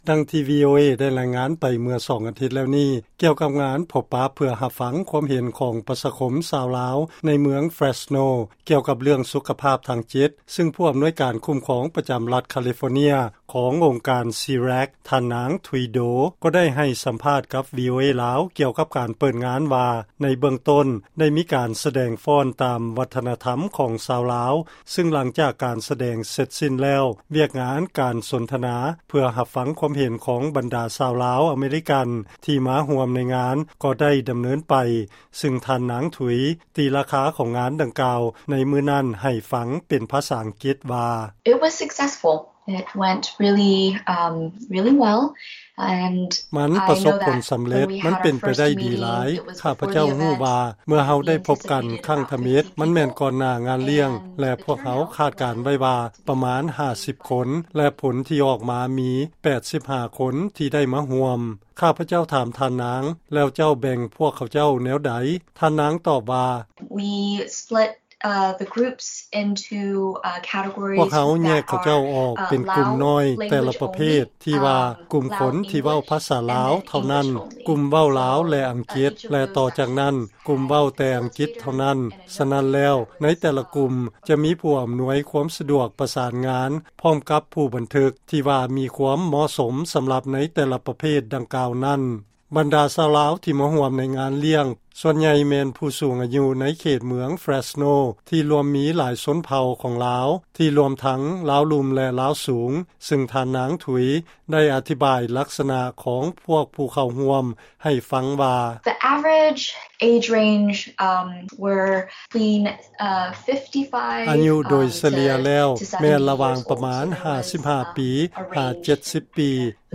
ຟັງລາຍງານ ອັງການ SEARAC ແລະ FIRM ຈັດງານພົບປະເພື່ອຟັງຄວາມເຫັນ ຂອງປະຊາຄົມຊາວລາວ ກ່ຽວກັບເລື້ອງສຸຂະພາບທາງຈິດ ຢ່າງສຳເລັດຜົນ